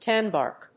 音節tán・bàrk
アクセント・音節tán・bàrk